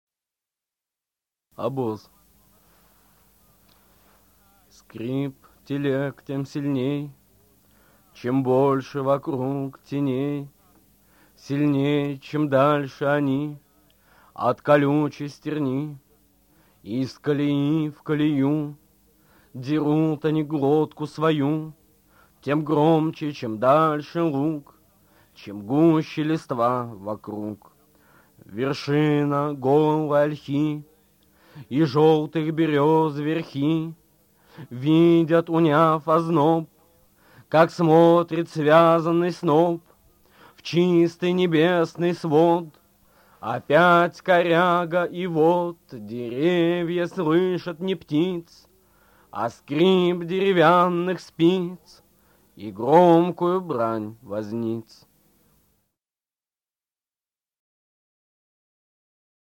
Brodskiy-Oboz-chitaet-avtor-stih-club-ru.mp3